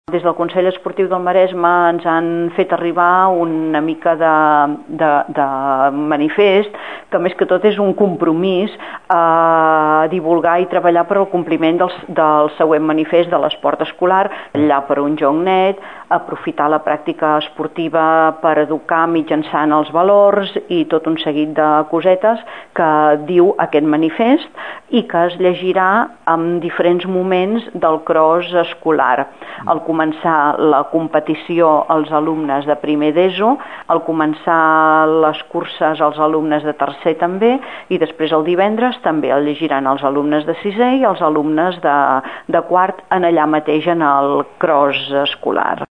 Aquest any, i com a novetat, durant el transcurs de la competició es llegirà un manifest sobre els valors que s’aprenen i es promouen a través de l’esport. Ens ho explica la regidora.